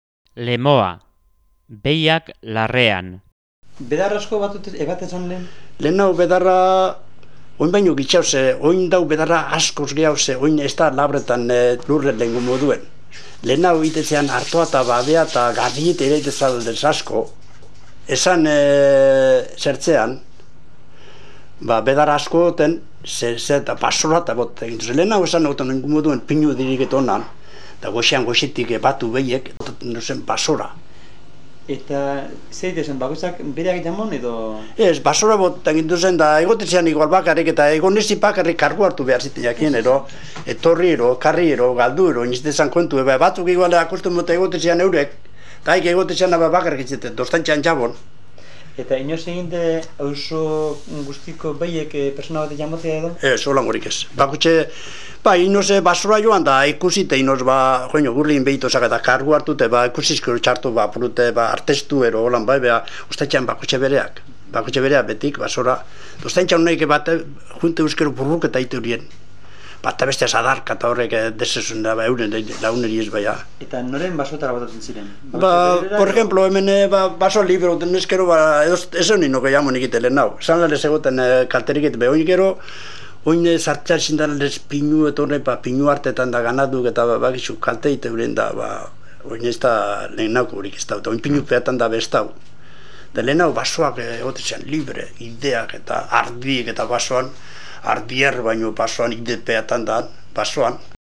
Lemoa.mp3